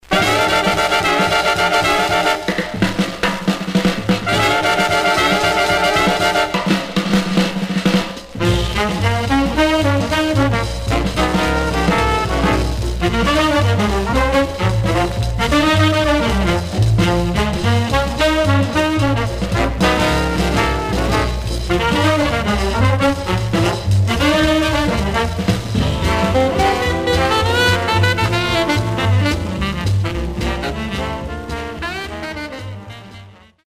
Stereo/mono Mono
Jazz